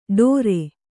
♪ ḍōre